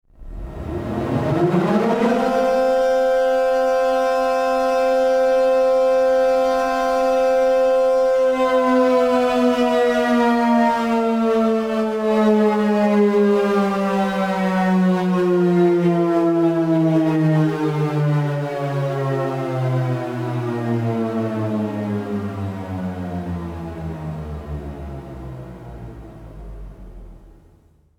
Loud And Alarming Air Raid Siren Sound Effect